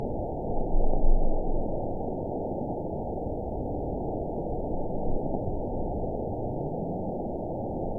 event 920142 date 02/29/24 time 00:43:08 GMT (1 year, 2 months ago) score 9.19 location TSS-AB05 detected by nrw target species NRW annotations +NRW Spectrogram: Frequency (kHz) vs. Time (s) audio not available .wav